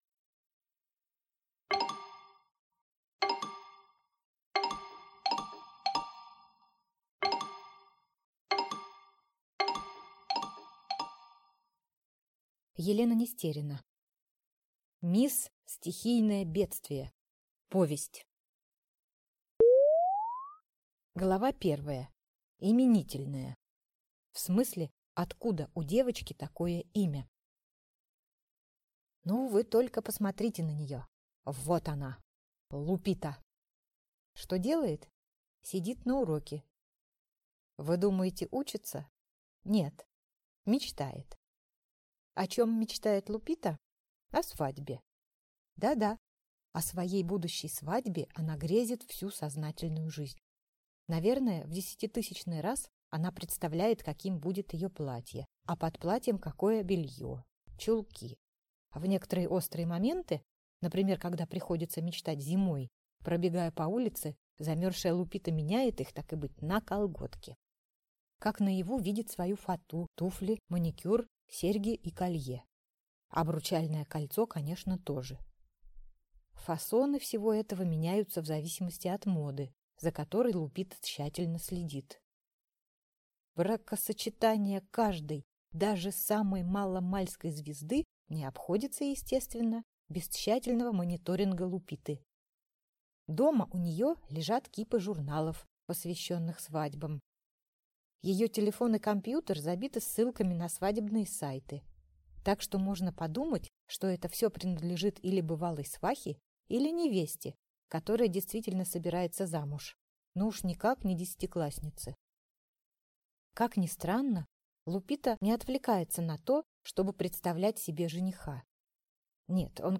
Аудиокнига Мисс стихийное бедствие | Библиотека аудиокниг